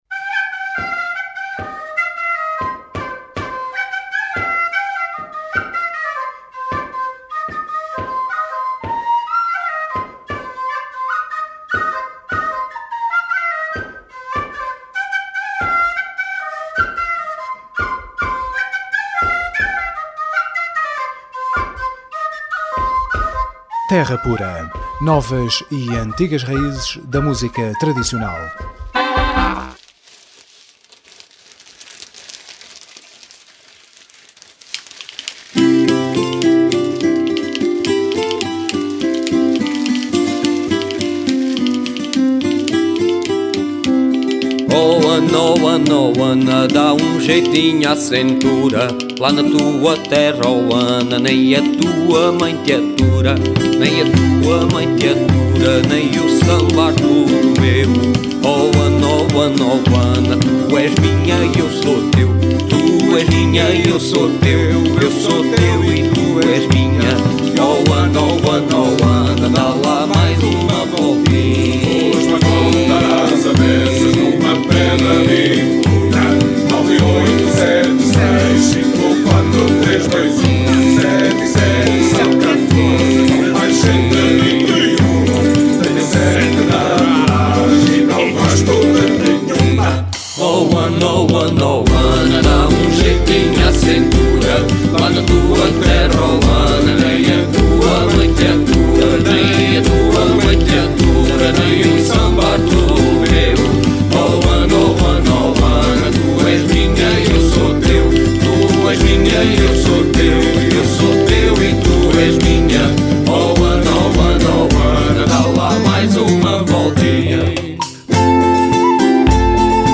Terra Pura 07ABR10: Entrevista No Mazurka Band – Crónicas da Terra
Emissão especial terra de Abrigo com No Mazurka Band.